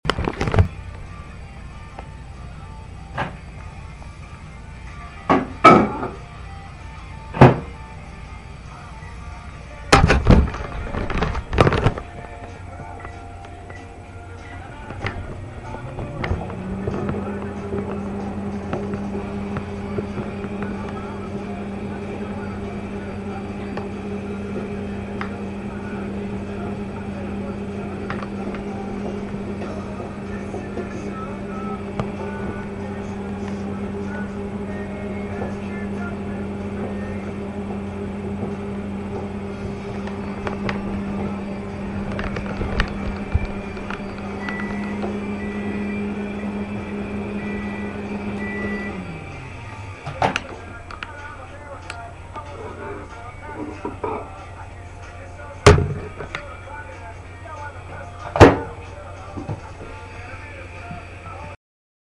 Sounds Heard: Irish music, microwave door opening/shutting, microwave humming, cup hitting/moving on glass plate, buttons and microwave beeping
Audio-Irish-Microwave1.mp3